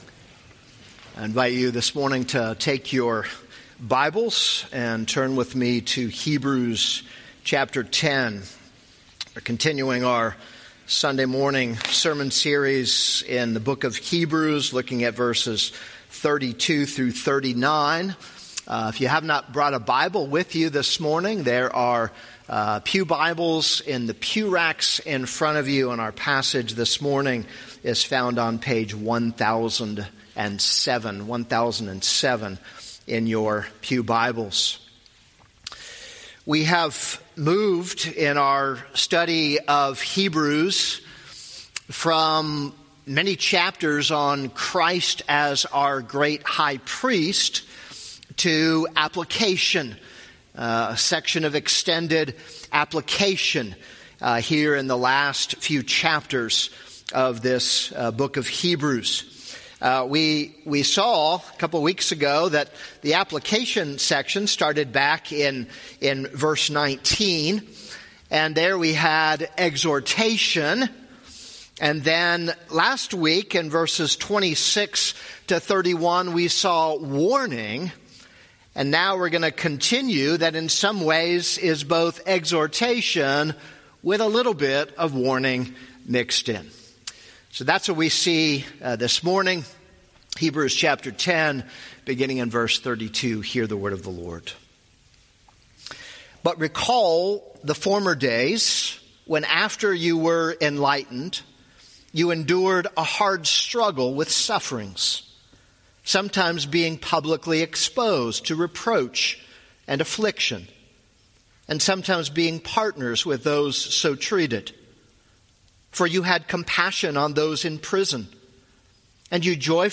This is a sermon on Hebrews 10:32-39.